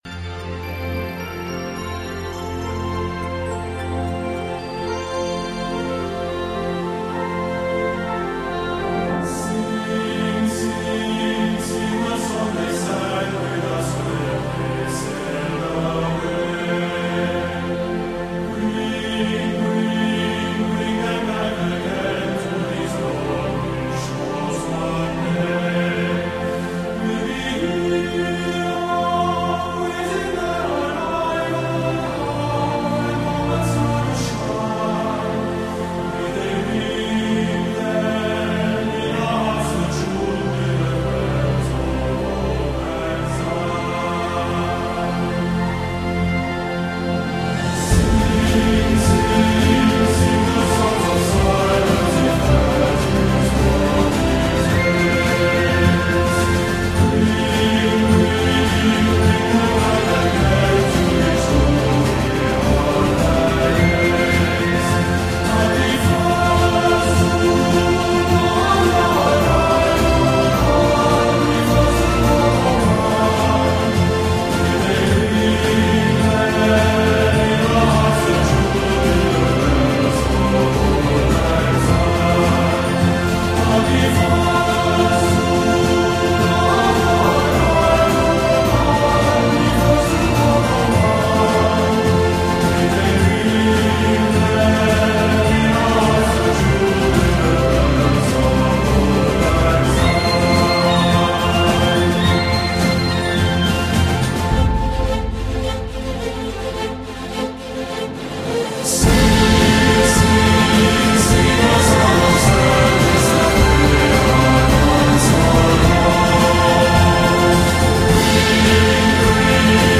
【New age】